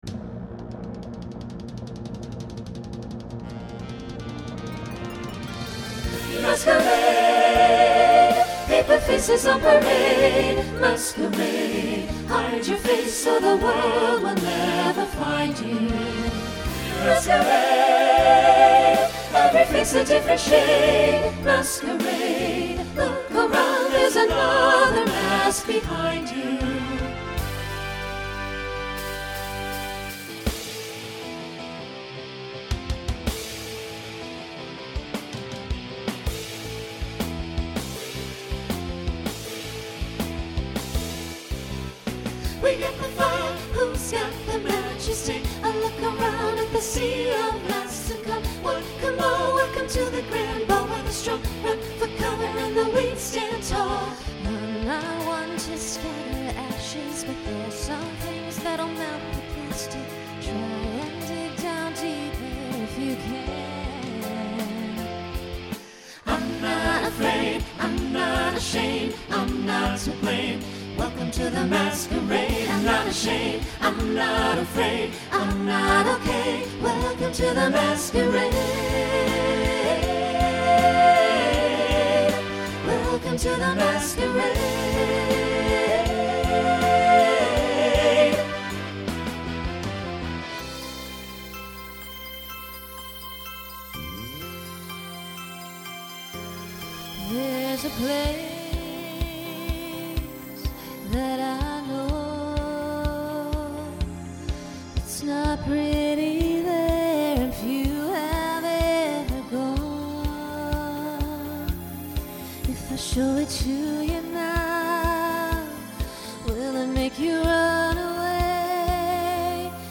Voicing SATB
Genre Broadway/Film , Pop/Dance , Rock